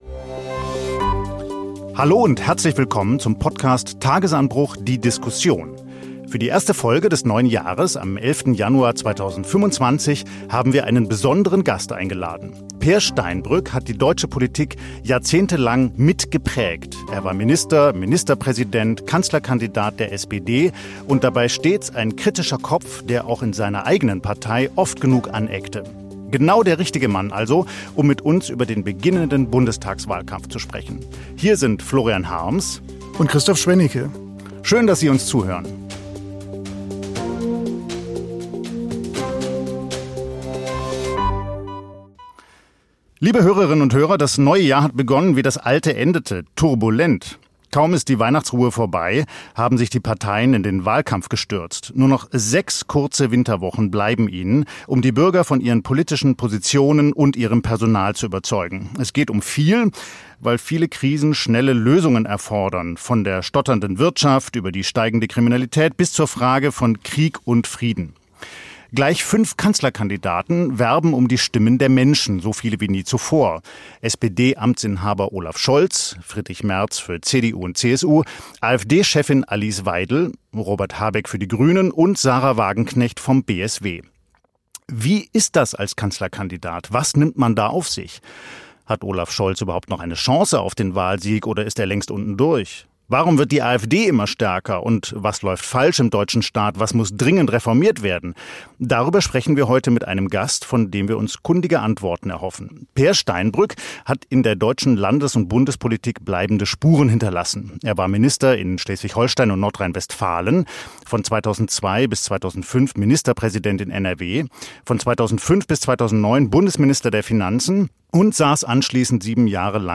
Im "Tagesanbruch"-Podcast findet Ex-Finanzminister Peer Steinbrück deutliche Worte. Das SPD-Urgestein übt scharfe Kritik an der eigenen Partei: Die Sozialdemokraten hätten ihre Kernklientel und historische Mission verloren.